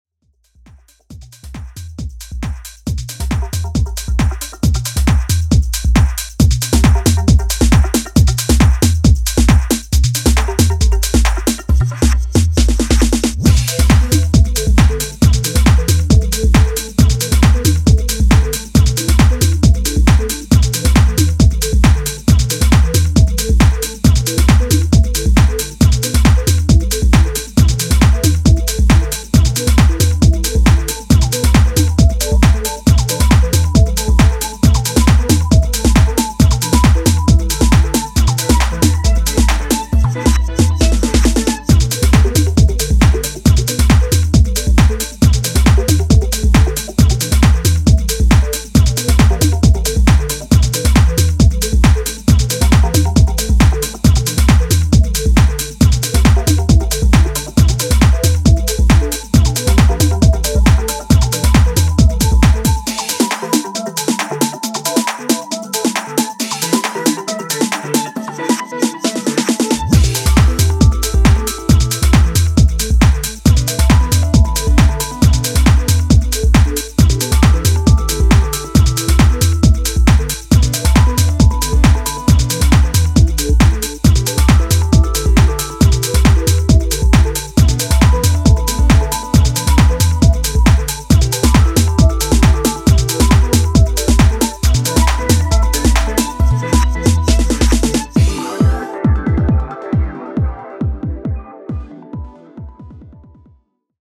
modern takes on the classic 90s UK tech-house sound
Minimal , Tech House